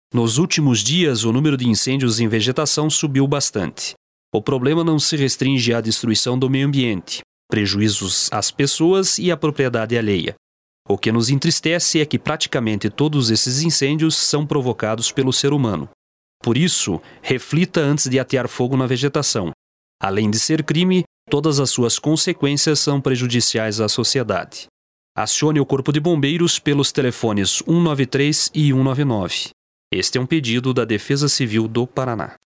Várias rádios receberam o spot e estão difundindo as informações da campanha durante sua programação.